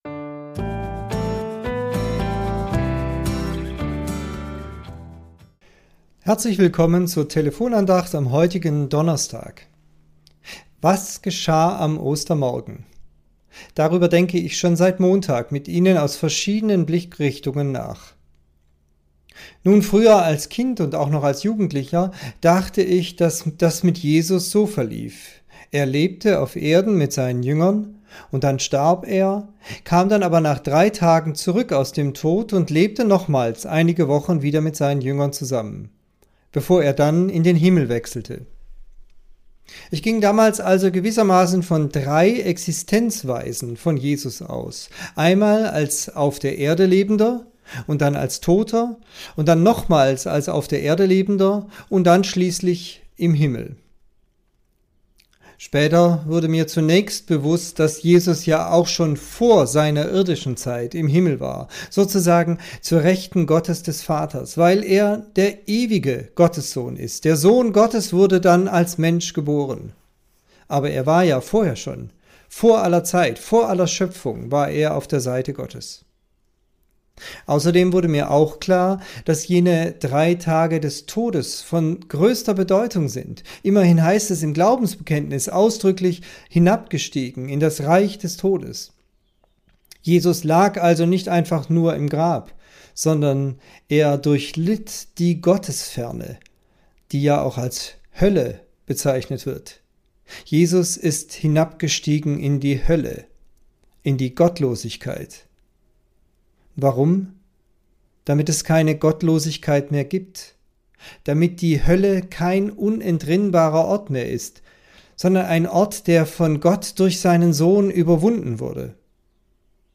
Tübinger Telefonandacht zur Tageslosung Podcast